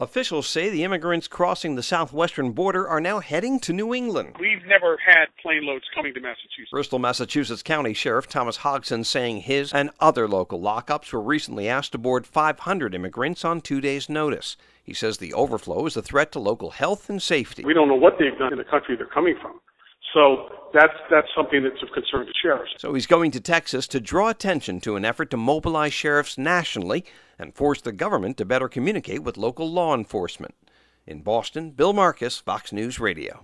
HAS MORE FROM BOSTON.